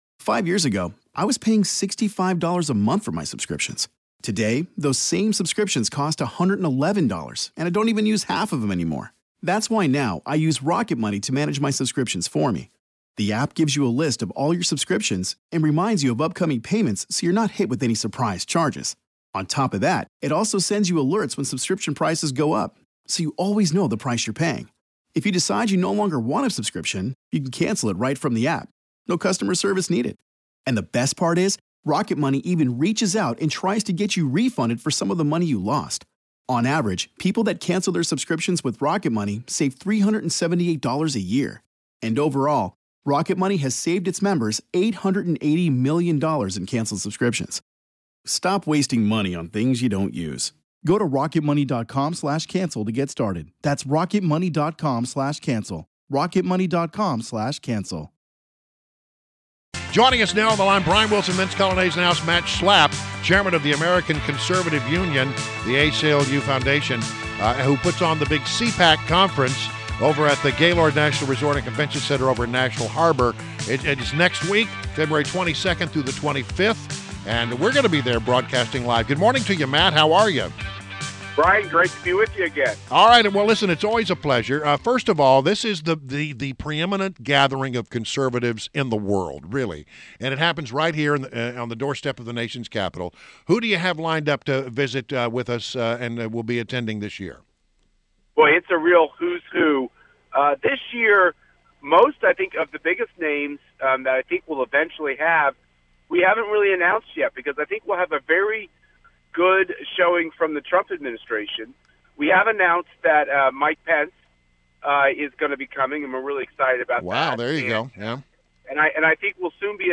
WMAL Interview - MATT SCHLAPP - 02.16.17
INTERVIEW — MATT SCHLAPP, Chairman of The American Conservative Union Foundation (ACU), the organization that hosts the annual Conservative Political Action Conference (CPAC) and also is Mr. Mercedes Schlapp